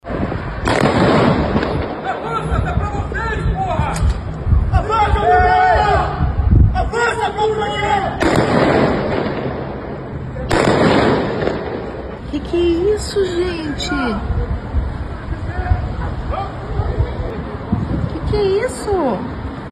Tiros de festim e gritaria: treinamento da PM assusta moradores em Curitiba – CBN Curitiba – A Rádio Que Toca Notícia
Registros feitos desde apartamentos da região mostram a movimentação de policiais, com disparos e gritos.
Conforme uma mensagem de informação interna da PM, foi utilizada munição de festim e ruas da região foram bloqueadas para a ação.